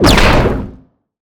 energy_blast_large_04.wav